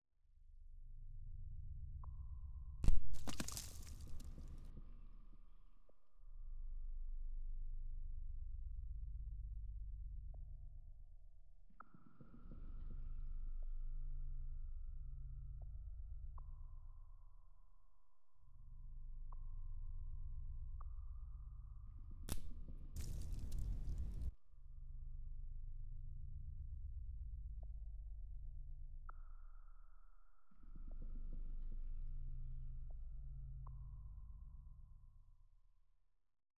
CaveAmbience01.ogg